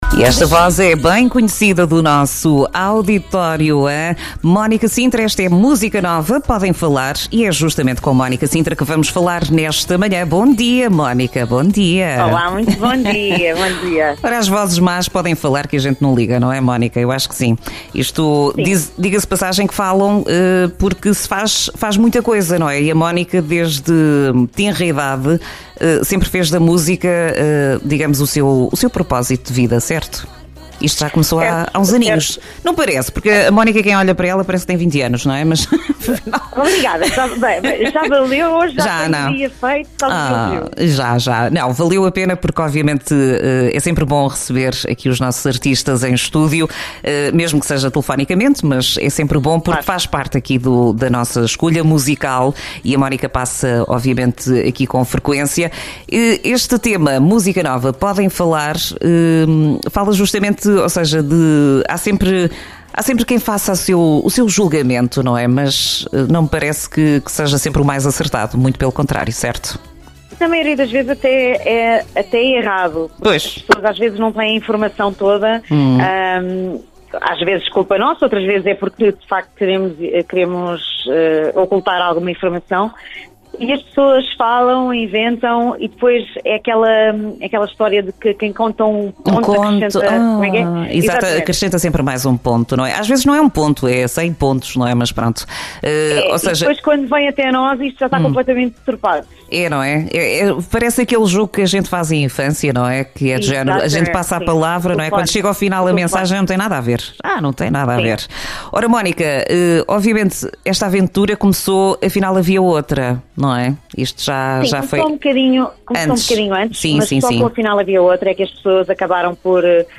Entrevista dia 23 de Outubro, de Mónica Sintra em direto no programa Manhãs NoAr.
ENTREVISTA-MONICA-SINTRA.mp3